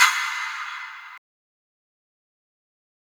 soft-hitfinish.wav